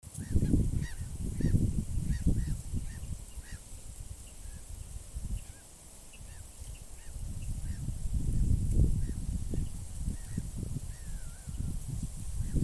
Water Rail, Rallus aquaticus
StatusVoice, calls heard